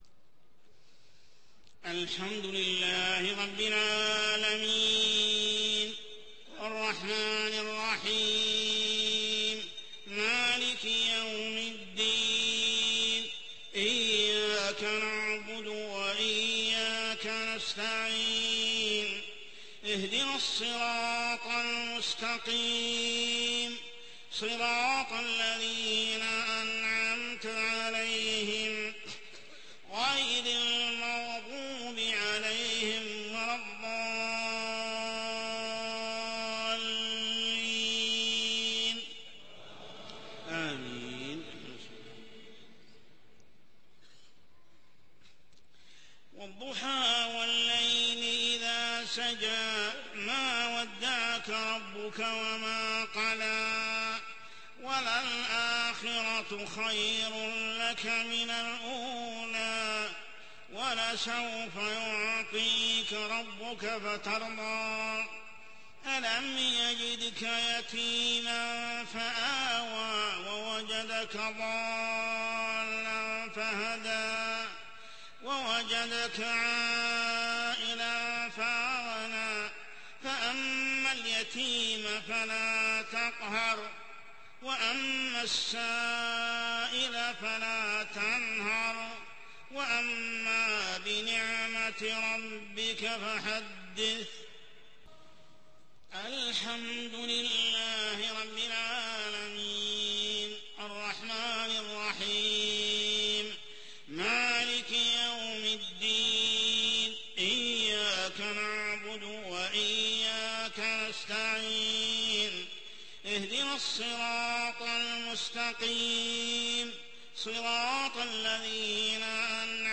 صلاة العشاء عام 1428هـ سورتي الضحى و الشرح كاملة | Isha prayer Surah Ad-duha and Ash-Sharh > 1428 🕋 > الفروض - تلاوات الحرمين